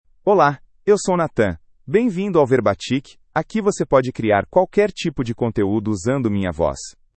NathanMale Portuguese AI voice
Nathan is a male AI voice for Portuguese (Brazil).
Voice sample
Listen to Nathan's male Portuguese voice.
Male
Nathan delivers clear pronunciation with authentic Brazil Portuguese intonation, making your content sound professionally produced.